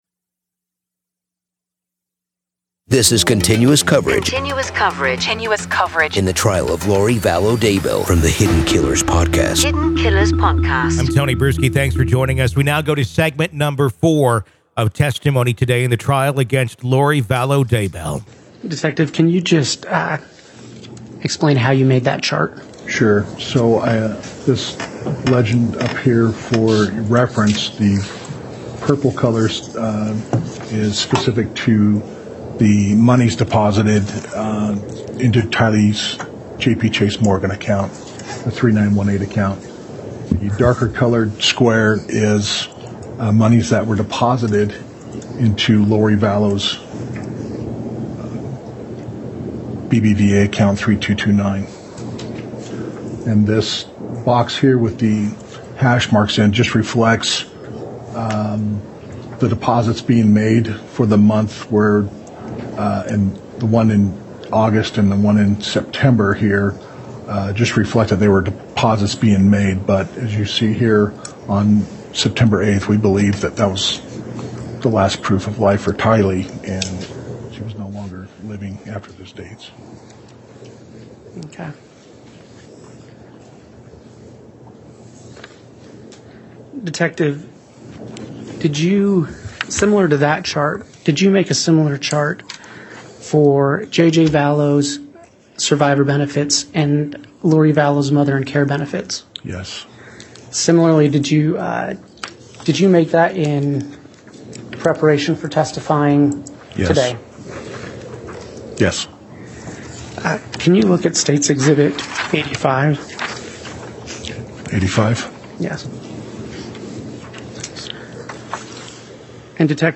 LISTEN: The Trial Of Lori Vallow Daybell Day 6 Part 4 | Raw Courtroom Audio